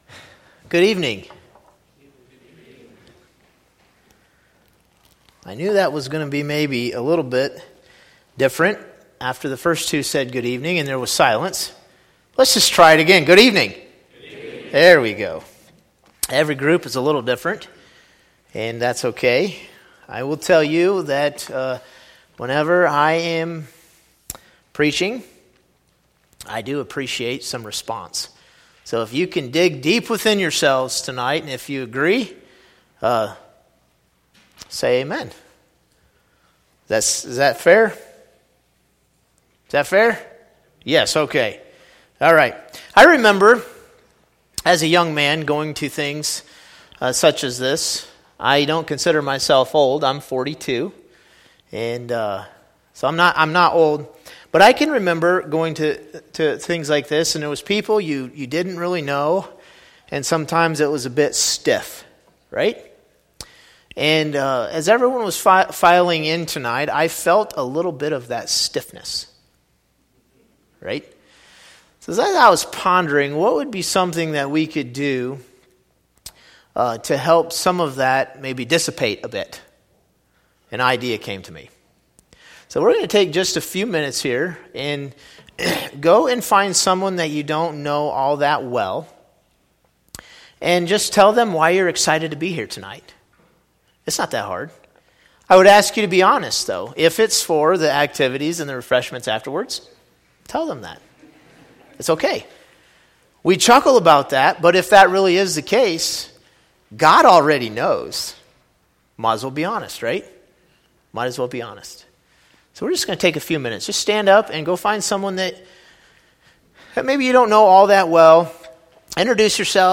A podcast of sermons by Salem Mennonite Church.